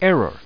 ERROR.mp3